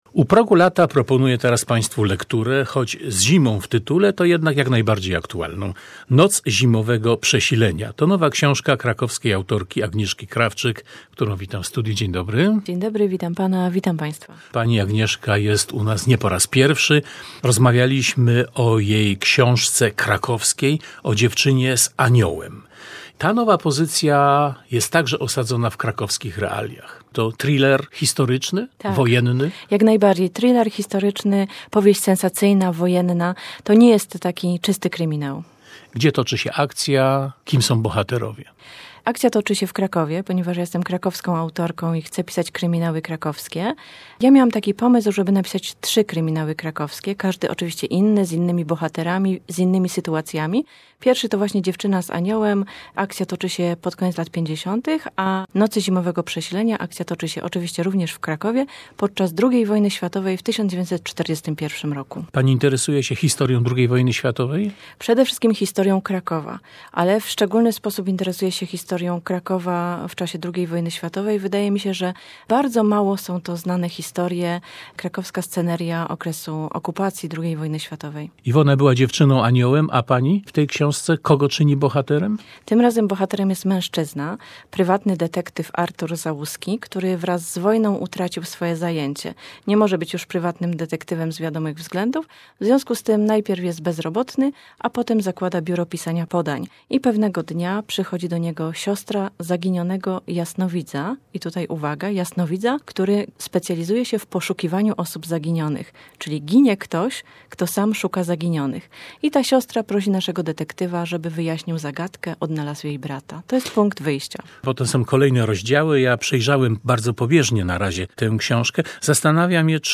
Wywiad w Radio Kraków – do posłuchania!